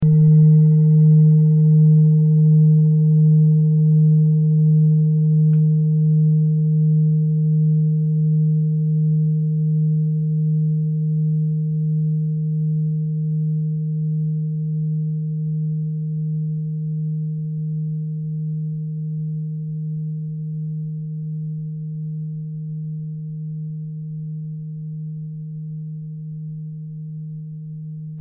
Klangschale Bengalen Nr.29
Sie ist neu und wurde gezielt nach altem 7-Metalle-Rezept in Handarbeit gezogen und gehämmert.
Eros-Ton:
klangschale-ladakh-29.mp3